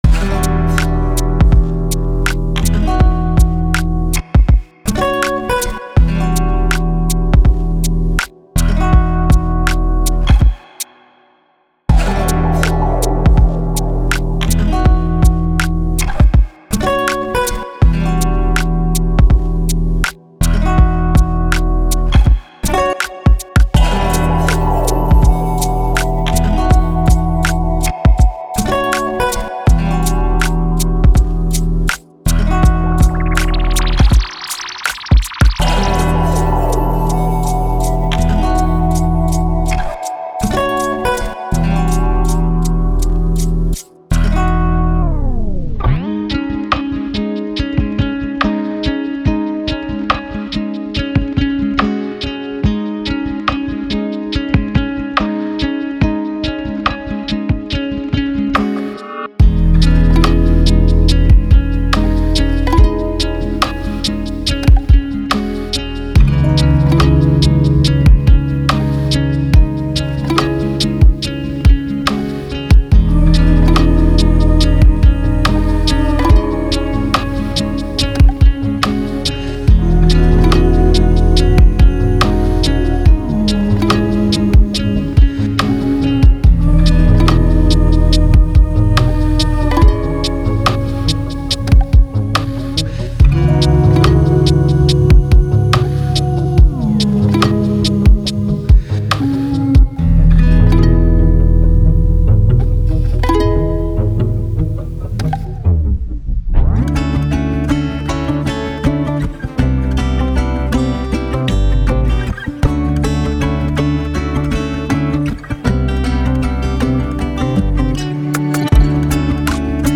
Genre:Lo-Fi Hip Hop
キックドラムはギター録音から作られ、スネア、ハイハット、クラップにはビートボックスがブレンドされています。
ギターやウクレレの演奏は、M32コンソールとノイマンマイクを使用して本物のパフォーマンスを収録しました。